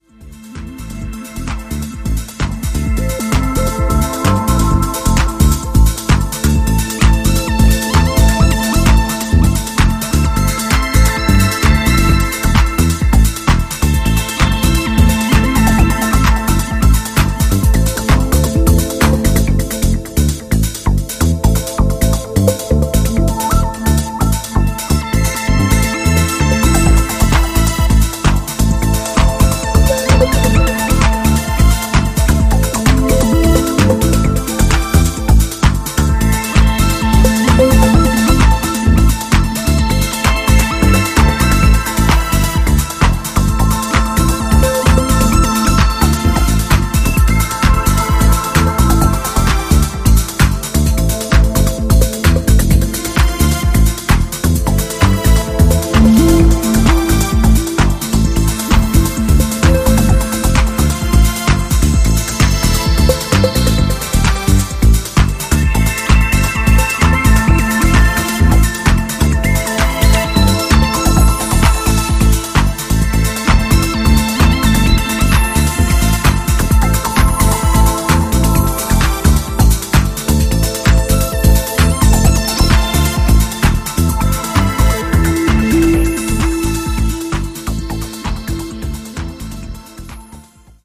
デトロイト古参ファンから新規ファンまで幅広く届いて欲しい、オールドスクール愛溢れる作品です。